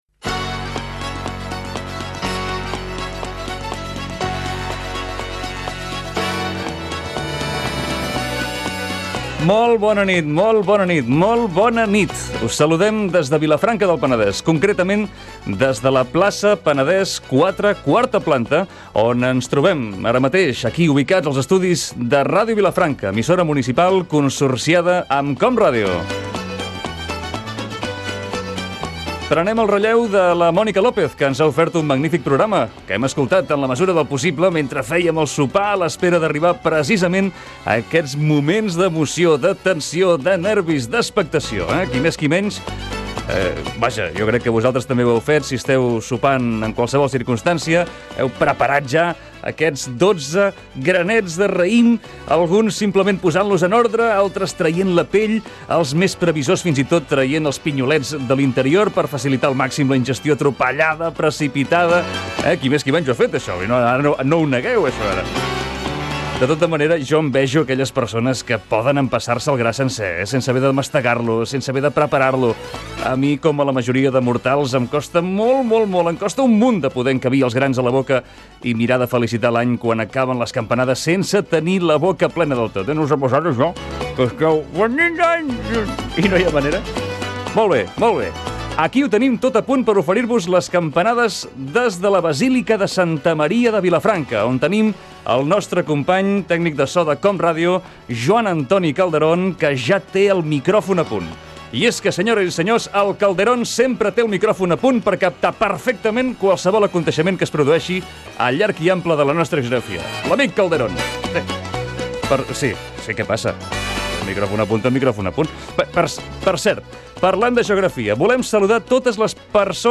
Salutació de Joan Aguado, alcalde de Vilafranca. Campanades des de la Basílica de Santa Maria de Vilafanca del Penedès.
Entreteniment